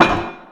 door_metal_large_close2.wav